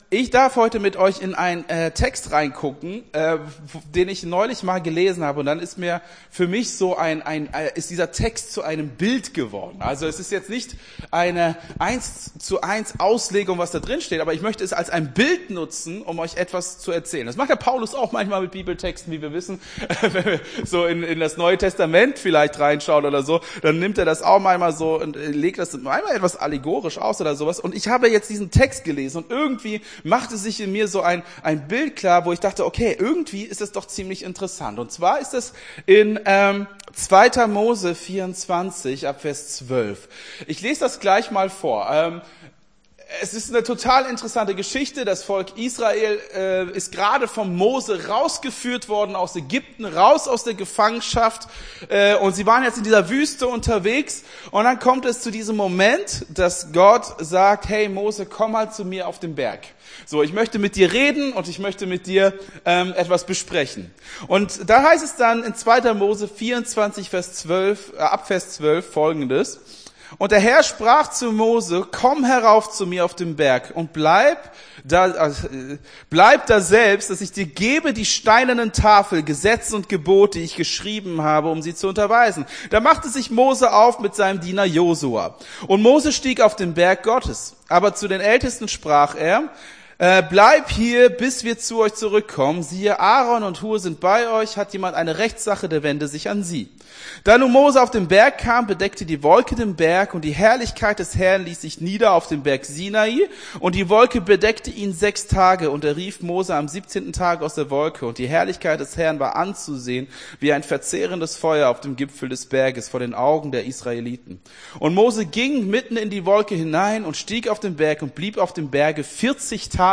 Gottesdienst 15.10.23 - FCG Hagen